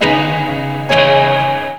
RIFFGTR 16-L.wav